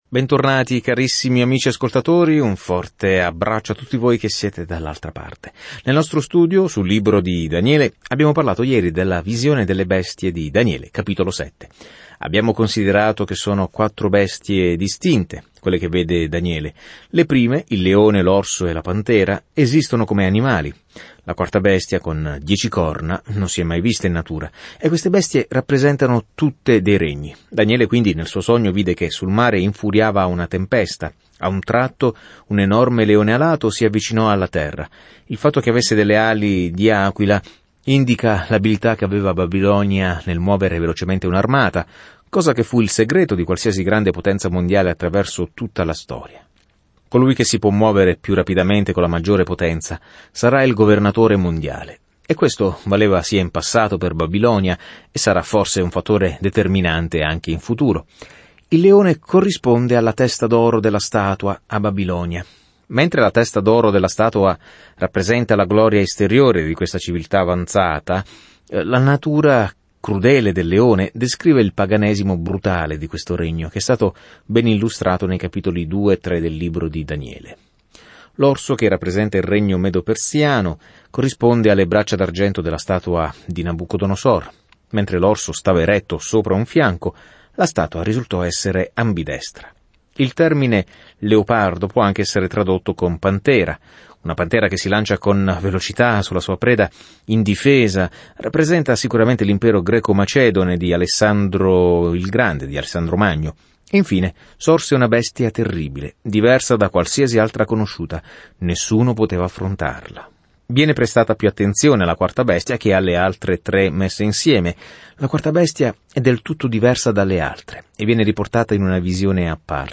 Scrittura Daniele 7:13-27 Giorno 12 Inizia questo Piano Giorno 14 Riguardo questo Piano Il libro di Daniele è sia la biografia di un uomo che credette in Dio sia una visione profetica di chi alla fine governerà il mondo. Viaggia ogni giorno attraverso Daniele mentre ascolti lo studio audio e leggi versetti selezionati della parola di Dio.